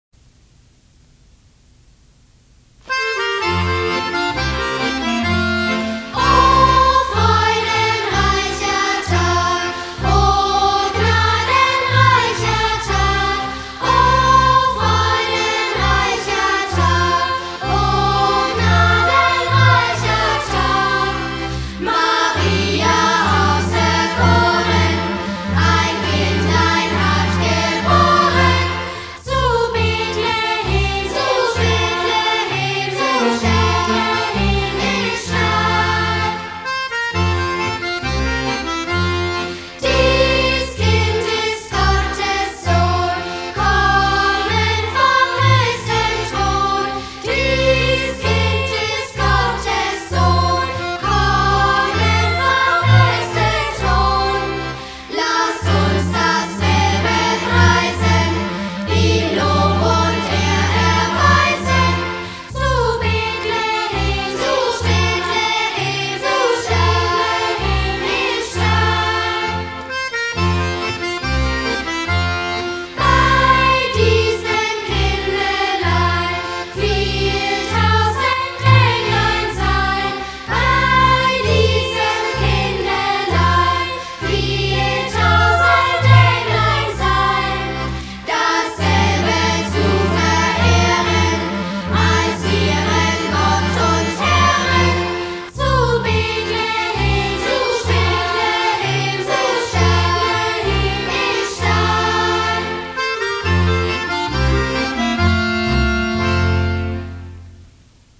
2009 - Auftritt
Fränkischen Sängerbund in der Heiligkreuzkirche
Theater- und Konzertkinderchor Coburg